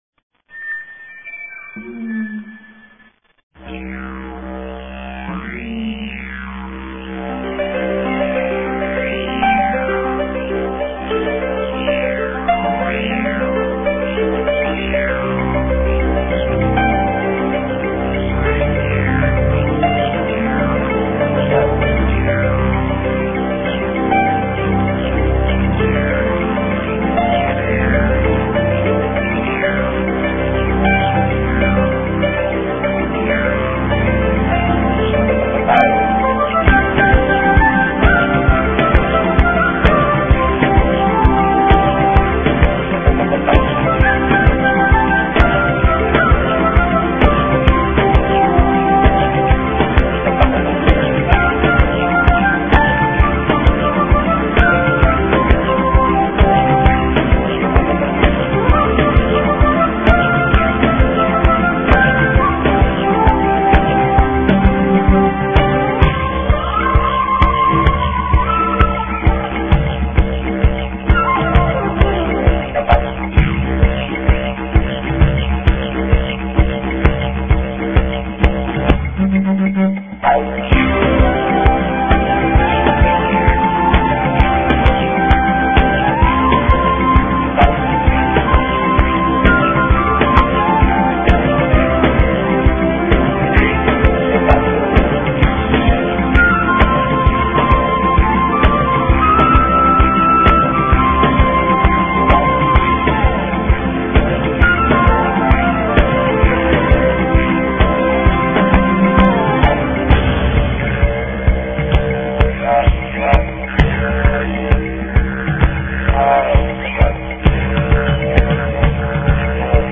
Non-traditional Didjeridu